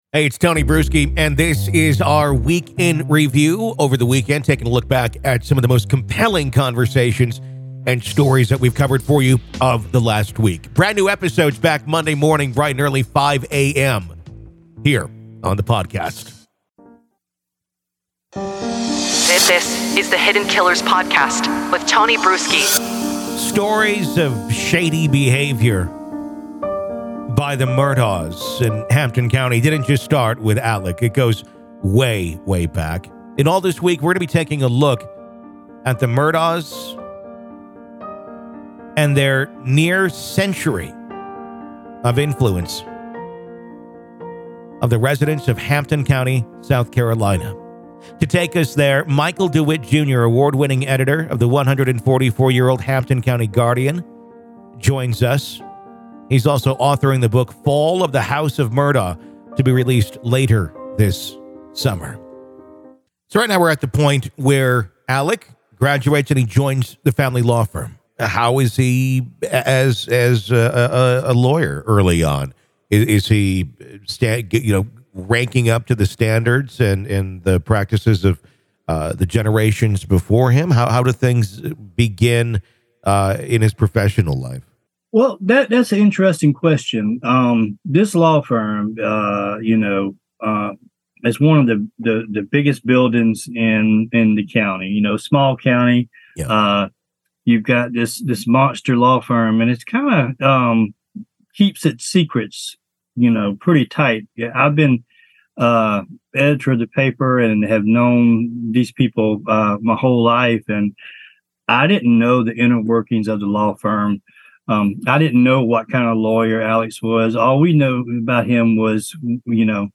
Expect thoughtful analysis, informed opinions, and thought-provoking discussions that go beyond the 24-hour news cycle.